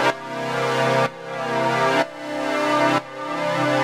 Index of /musicradar/sidechained-samples/125bpm
GnS_Pad-alesis1:2_125-C.wav